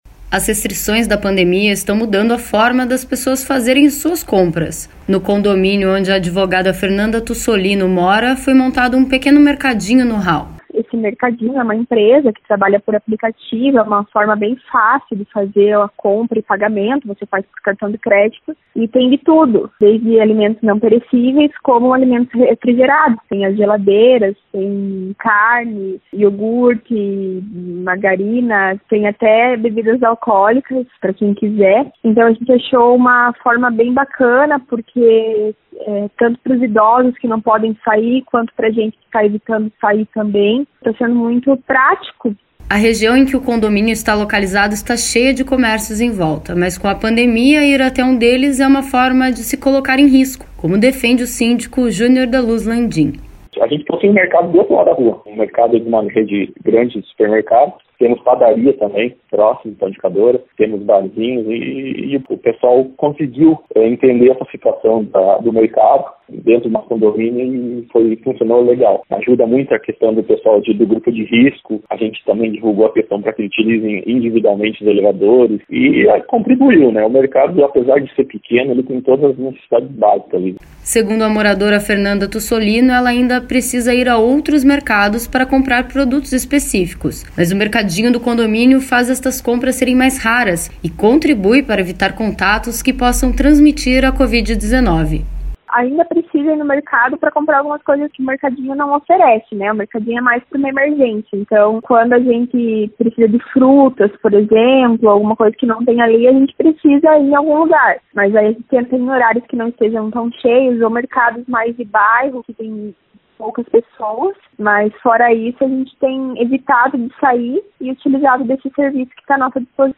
Um mercadinho montado dentro de um condomínio de Curitiba foi a solução encontrada para evitar que moradores se colocassem em risco de contrair a Covid-19 na hora de fazer compras. Os detalhes na reportagem.